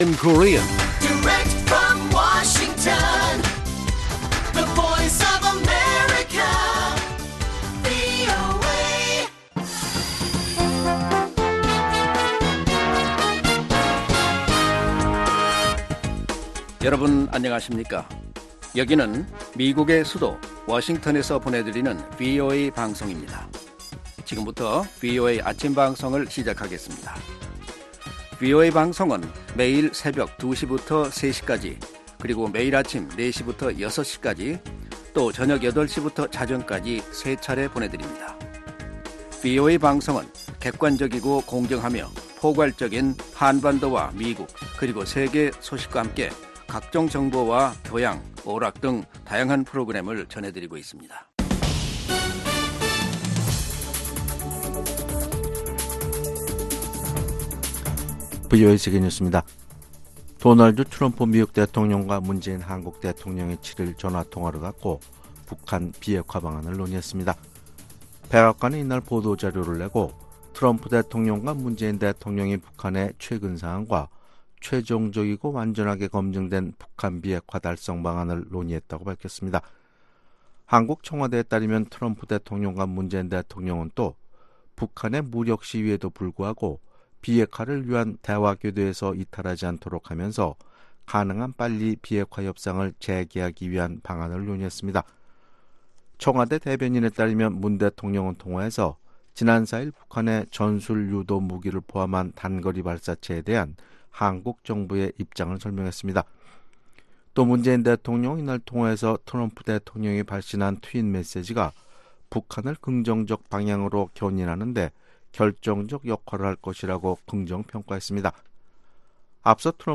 생방송 여기는 워싱턴입니다 5/8 아침
세계 뉴스와 함께 미국의 모든 것을 소개하는 '생방송 여기는 워싱턴입니다', 2019년 5월 8일 아침 방송입니다. ‘지구촌 오늘’은 도널드 트럼프 미국 대통령이 중국산 제품에 대한 관세 인상을 전격 예고한 가운데 중국 정부는 대미무역협상단의 미국 방문을 여전히 준비 중이라는 소식, ‘아메리카 나우’에서는 연방 의회 민주당 의원들이 윌리엄 바 법무장관에게 의회 모독죄를 적용하는 안을 추진 중이라는 이야기를 소개합니다.